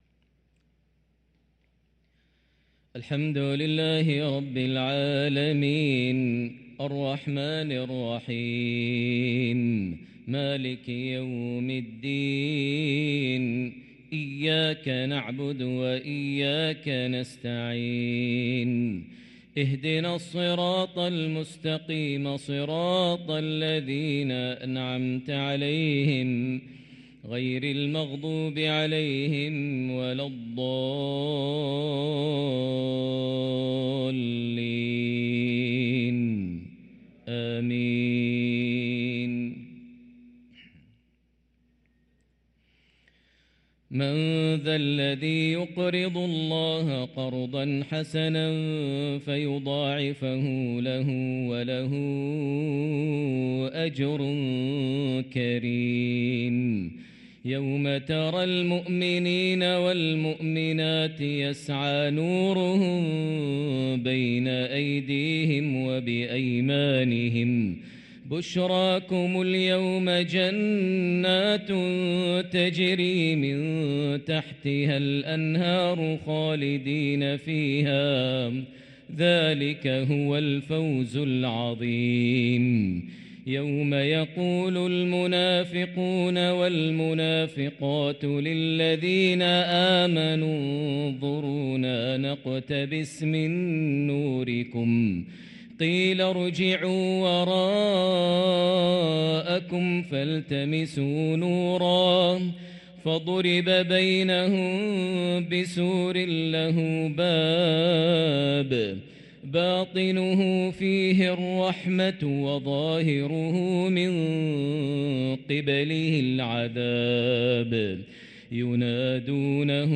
صلاة العشاء للقارئ ماهر المعيقلي 2 ربيع الآخر 1445 هـ
تِلَاوَات الْحَرَمَيْن .